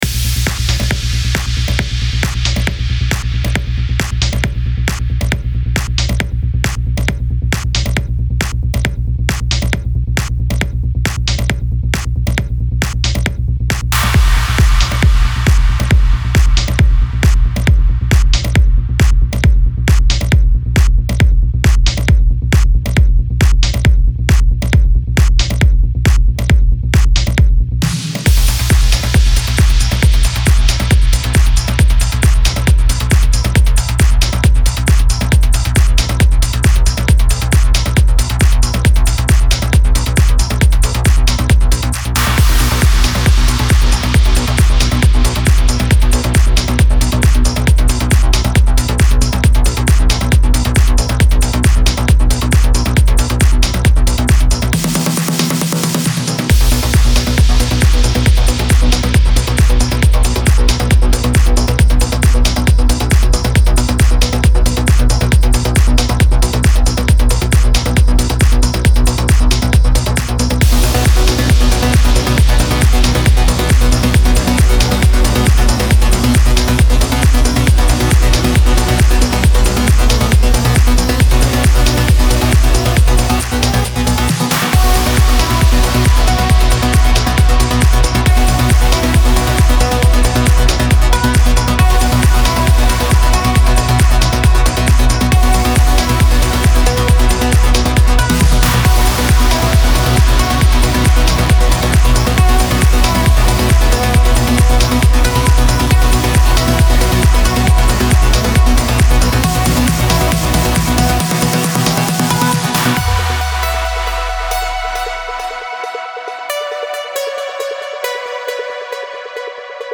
Стиль: Trance / Uplifting Trance / Progressive Trance